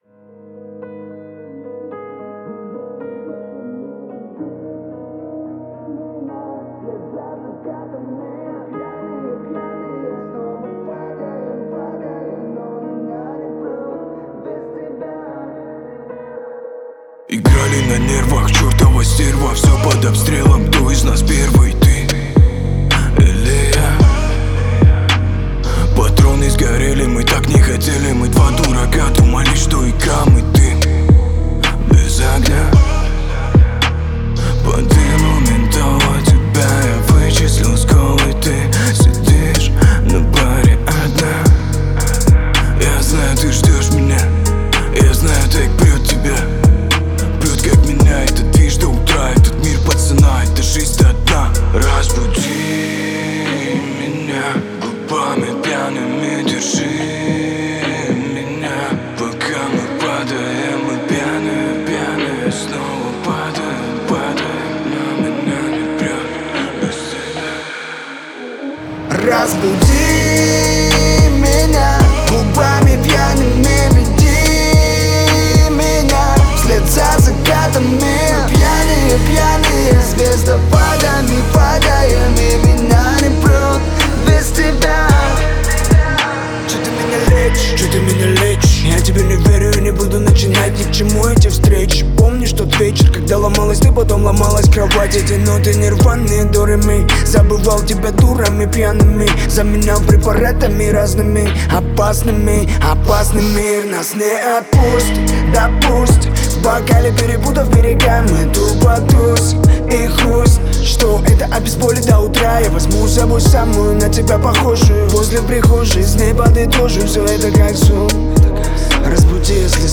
Жанр: Rap